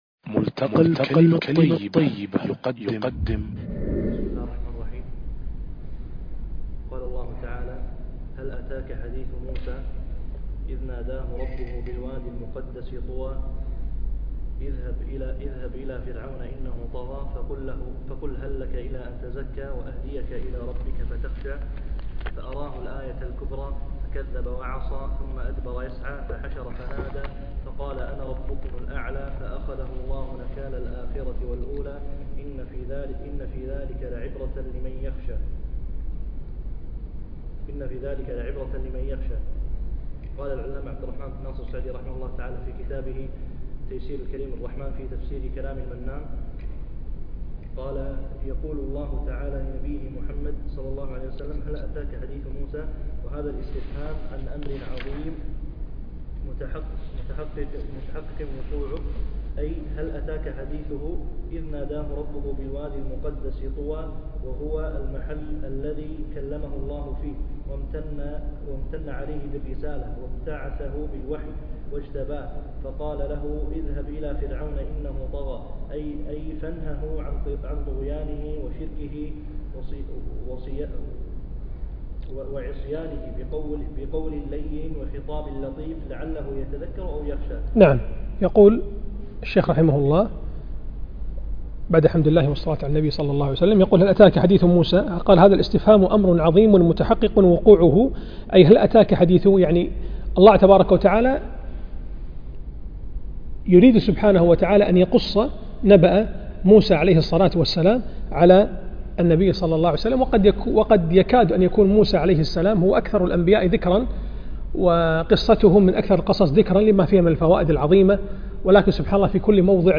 الدرس (5) التعليق على تفسير العلامة السعدي